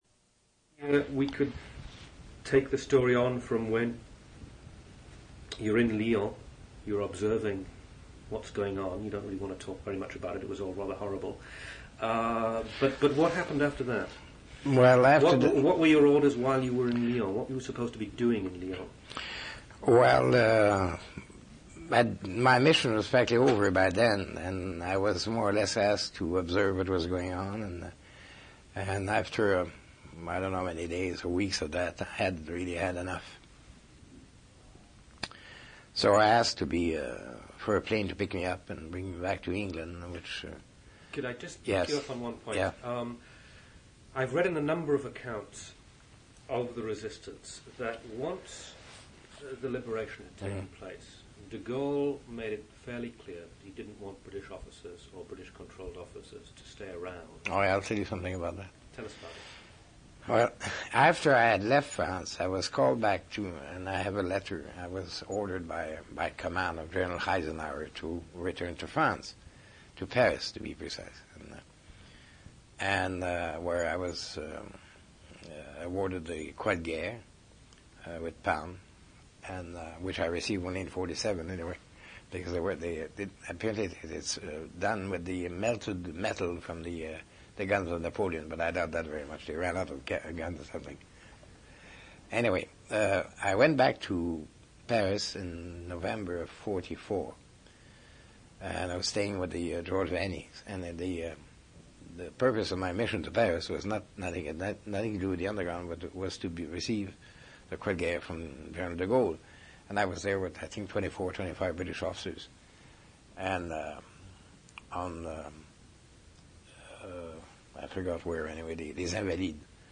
Interview took place on April 12, 1983.